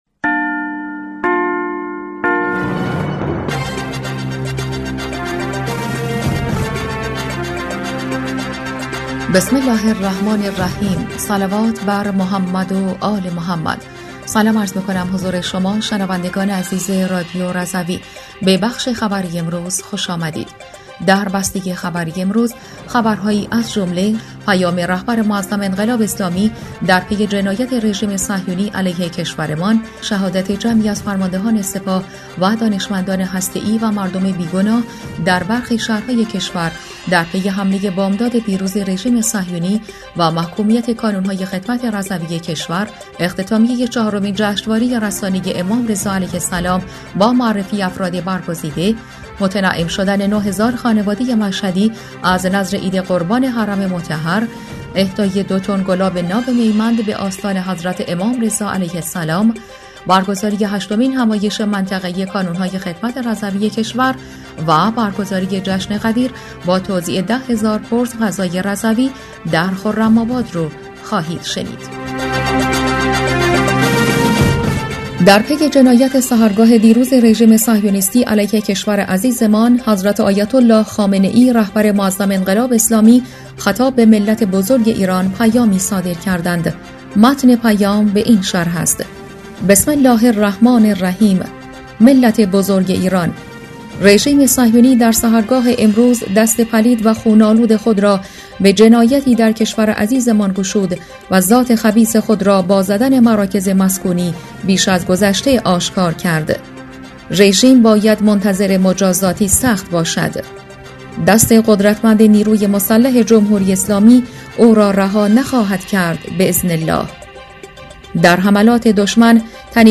در بسته خبری شنبه ۲۴ خردادماه ۱۴۰۴ رادیو رضوی خبرهایی از جمله محکومیت جنایات رژیم صهیونی از سوی کانون‌های خدمت سراسر کشور، پیام رهبر معظم انقلاب اسلامی در پی جنایت رژیم صهیونی علیه کشورمان، اختتامیه چهارمین جشنواره رسانه‌ای امام رضا علیه‌السلام با معرفی افراد برگزیده، متنعم شدن ۹ هزار خانواده مشهدی از نذر عید قربان حرم مطهر، اهدای ۲ تن گلاب ناب میمند به آستان حضرت امام رضا (ع)، برگزاری هشتمین…